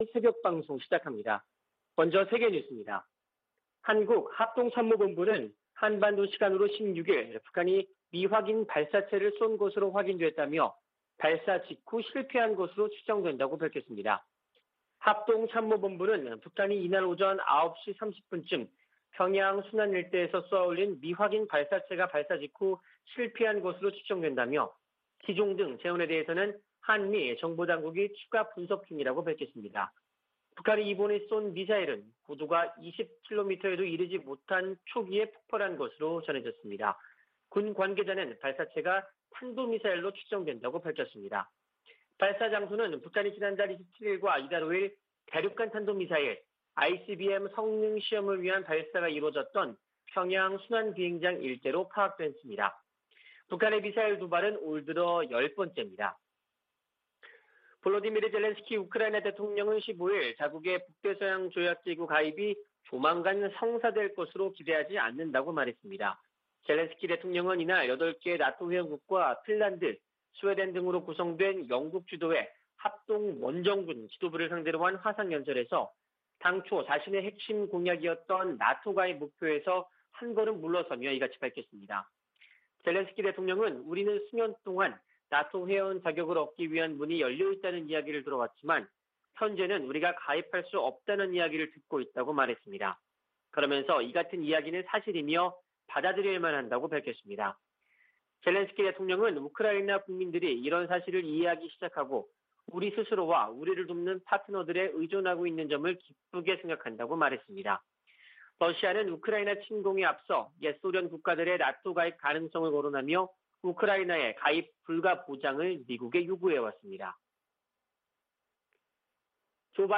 VOA 한국어 '출발 뉴스 쇼', 2022년 3월 17일 방송입니다. 한국 합동참모본부는 북한이 16일 평양 순안 일대에서 미확인 발사체를 발사했으나 실패한 것으로 추정된다고 밝혔습니다. 미 국무부는 북한의 탄도미사일 시험발사를 규탄하고, 대화에 나서라고 촉구했습니다. 유럽연합(EU)은 북한의 최근 탄도미사일 발사와 관련해 추가 독자 제재 부과를 검토할 수 있다는 입장을 밝혔습니다.